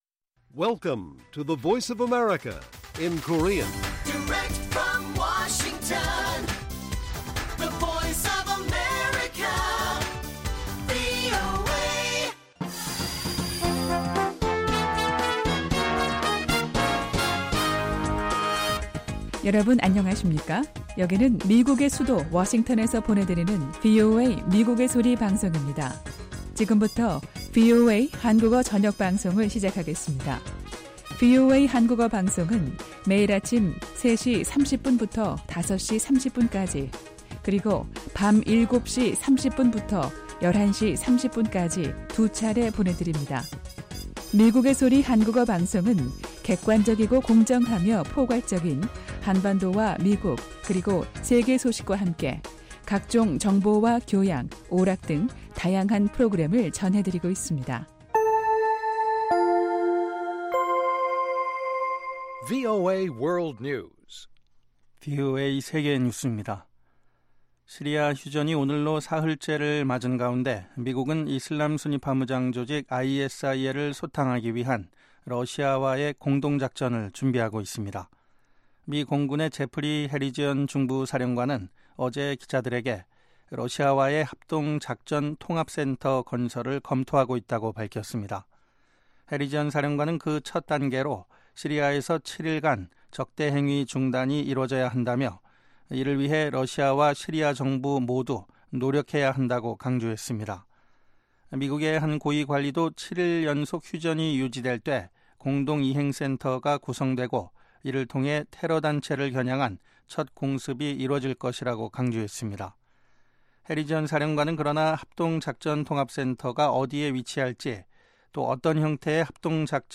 VOA 한국어 방송의 간판 뉴스 프로그램 '뉴스 투데이' 1부입니다. 한반도 시간 매일 오후 8:00 부터 9:00 까지, 평양시 오후 7:30 부터 8:30 까지 방송됩니다.